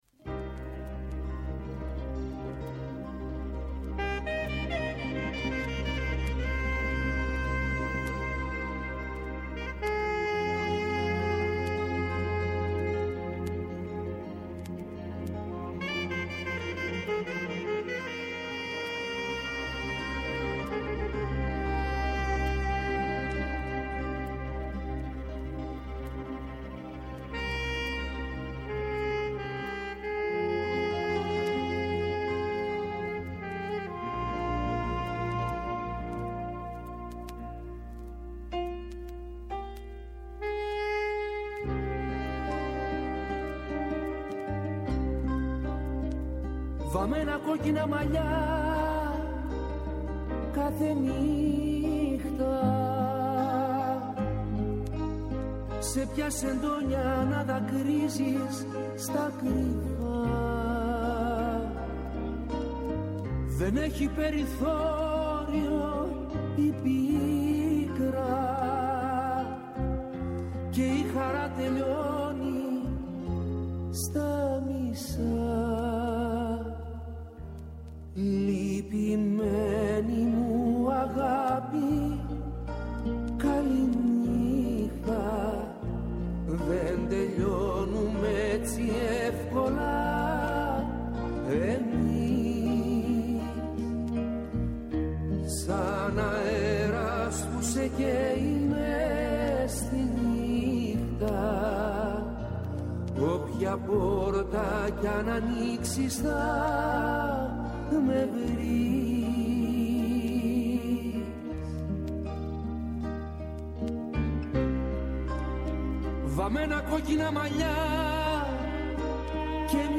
Καλεσμένη στην σημερινή εκπομπή η Καρυοφυλλιά Καραμπέτη.